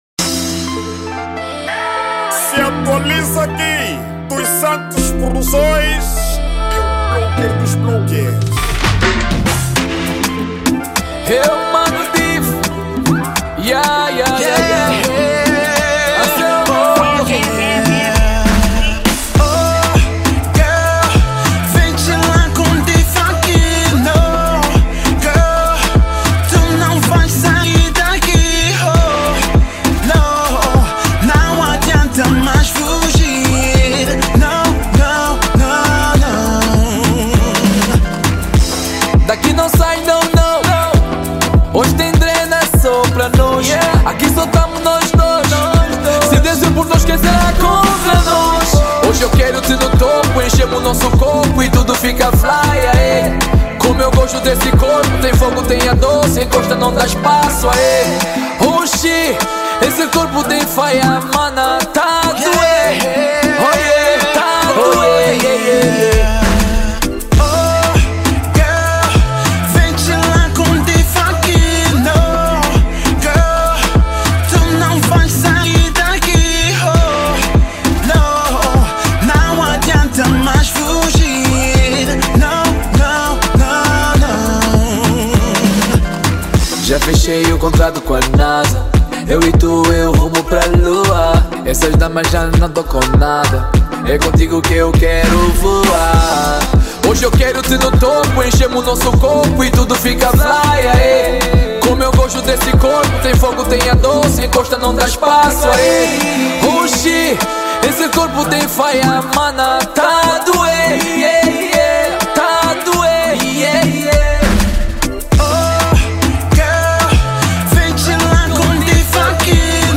ESTILO DA MÚSICA:  R&B